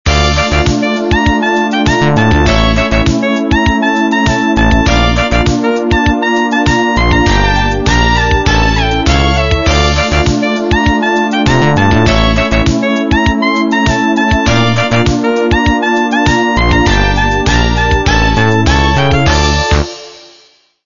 Самба